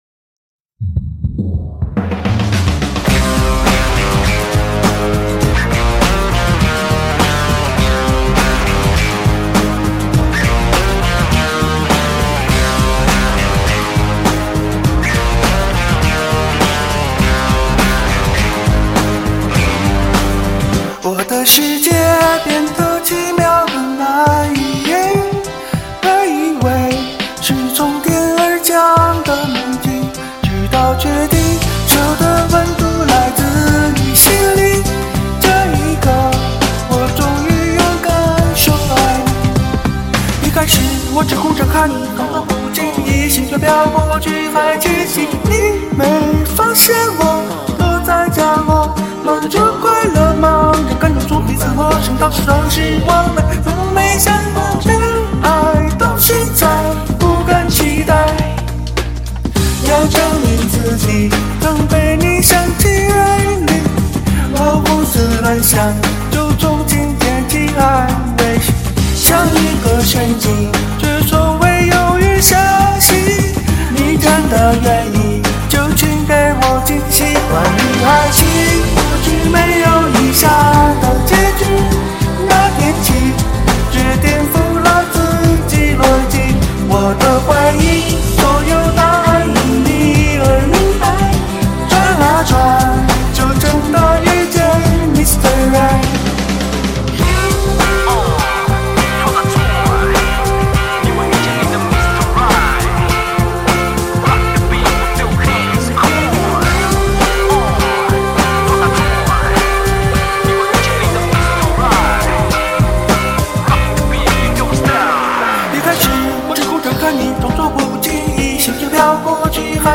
我经常不知不觉的模仿原唱，希望大家不要笑话
声如天籁，陶醉其中。